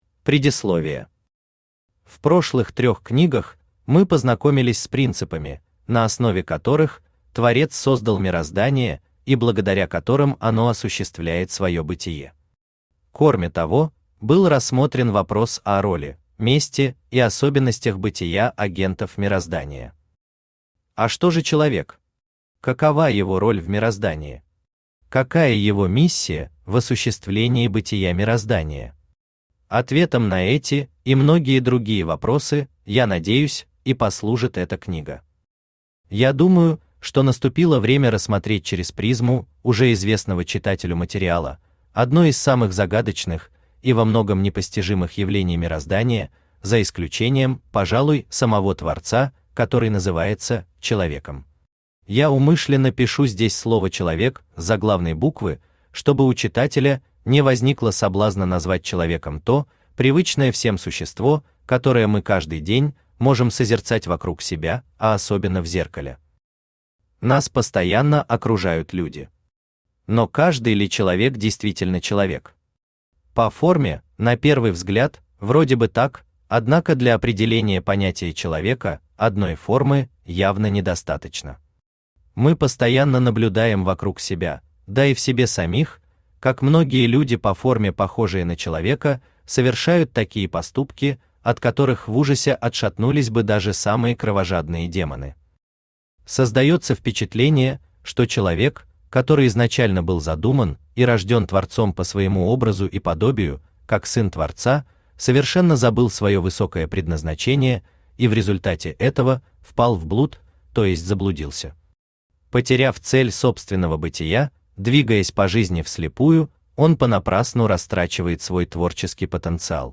Аудиокнига Девять принципов мироздания. Часть 4 | Библиотека аудиокниг
Aудиокнига Девять принципов мироздания. Часть 4 Автор Всеволод Всеволодович Протопопов Читает аудиокнигу Авточтец ЛитРес.